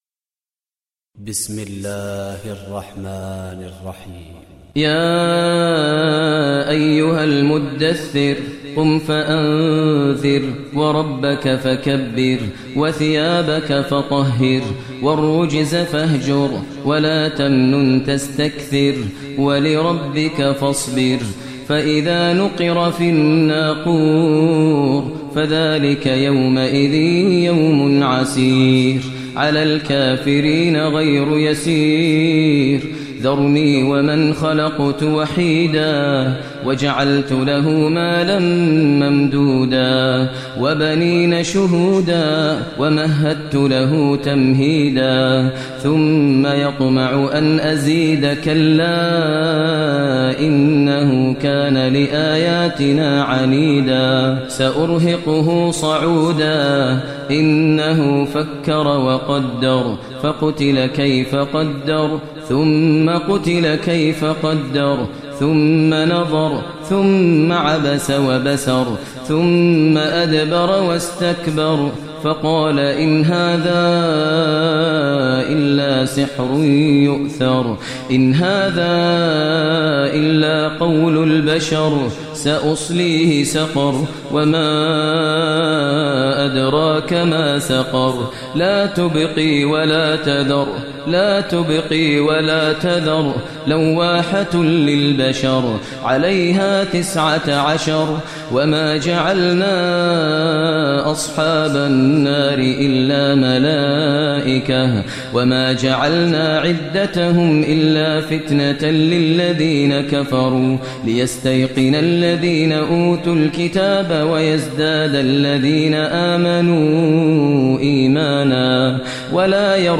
Surah Mudassir Recitation by Maher al Mueaqly
Surah Mudassir, listen online mp3 tilawat / recitation in Arabic recited by Imam e Kaaba Sheikh Maher al Mueqly.